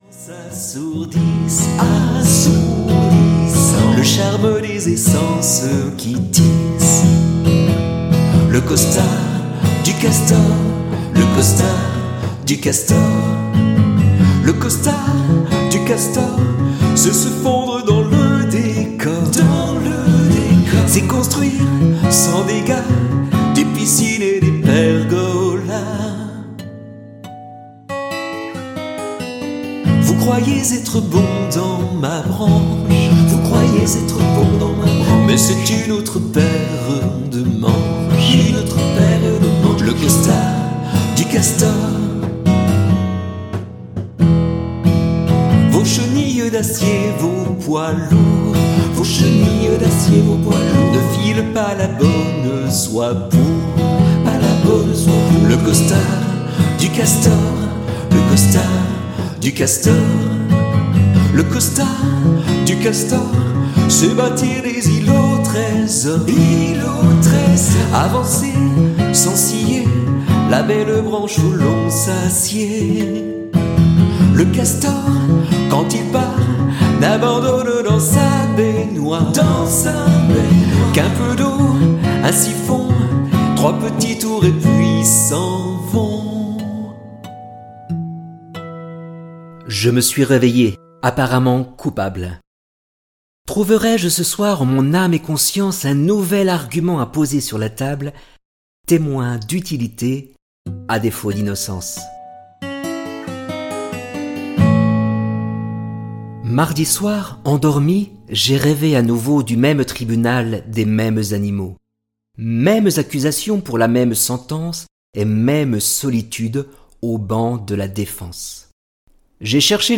qui nous raconte cette fable moderne et chante pour nous le récit des animaux en s'accompagnant merveilleusement à la guitare.